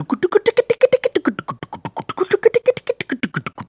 Голосовые эффекты звуки скачать, слушать онлайн ✔в хорошем качестве